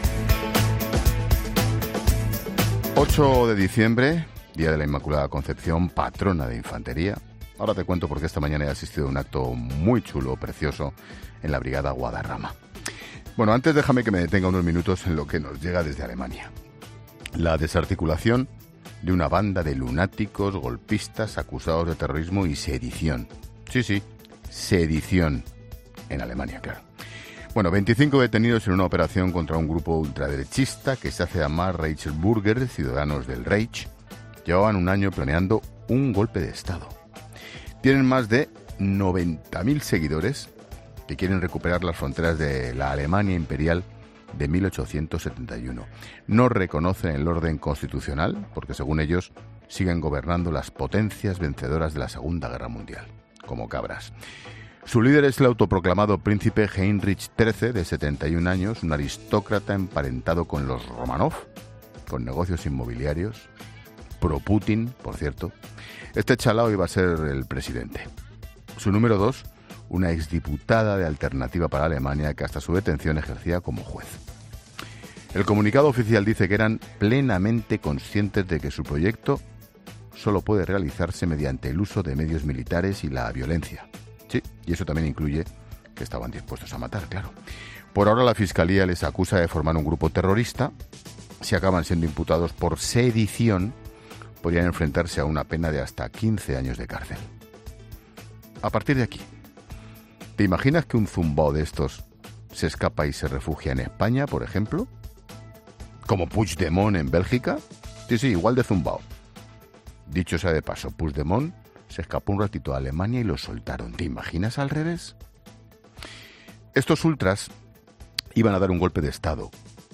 El director de La Linterna explica la operación en el país germano contra los Reichburger, un grupo ultraderechistas que planeaban un golpe de Estado